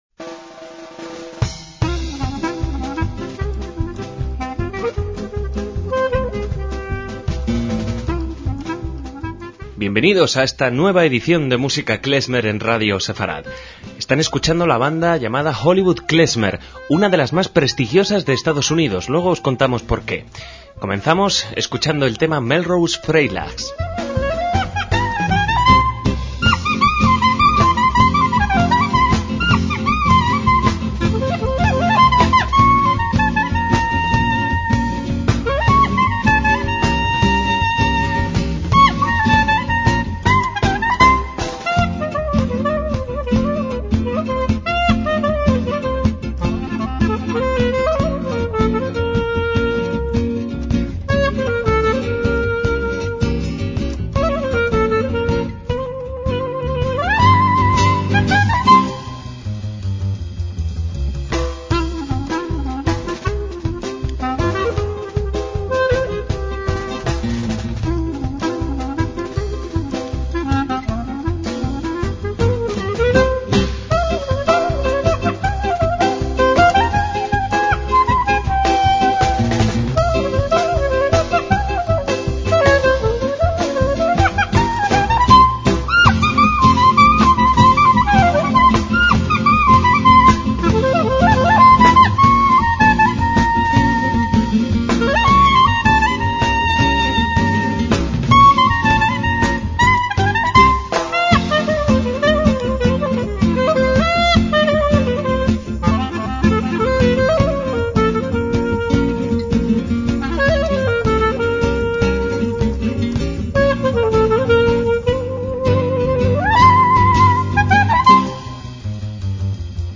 MÚSICA KLEZMER
guitarrista
al clarinete
al bajo